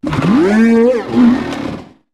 cyclizar_ambient.ogg